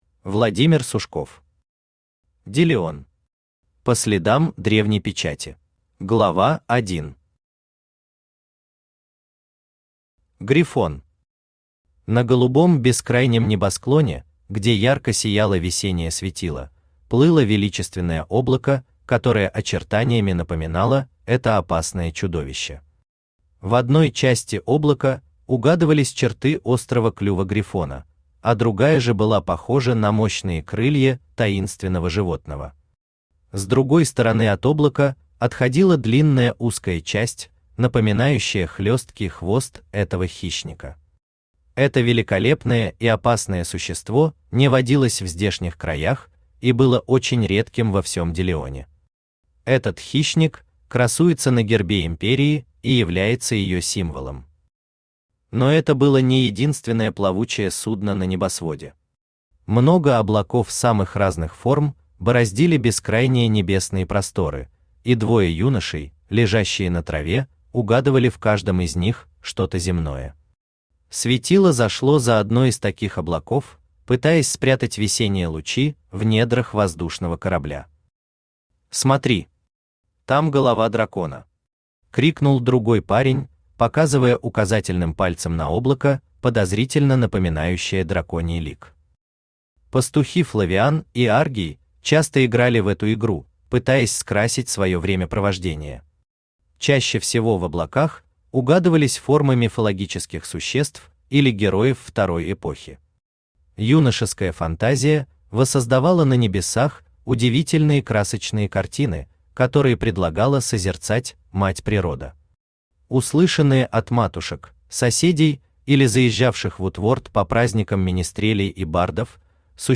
Аудиокнига Делион. По следам древней печати | Библиотека аудиокниг
По следам древней печати Автор Владимир Михайлович Сушков Читает аудиокнигу Авточтец ЛитРес.